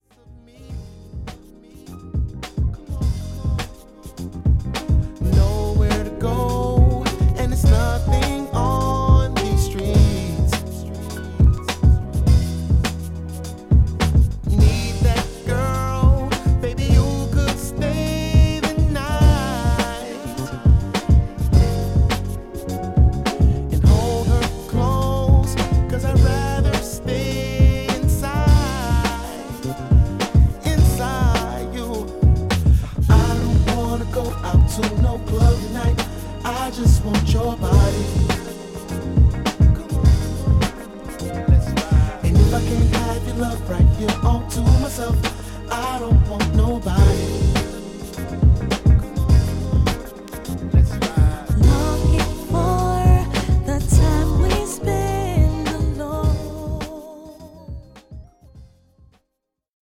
爽快でソウルフルなグルーヴ、R&B~クロスオーバー~ディープハウス好きまでバッチリです。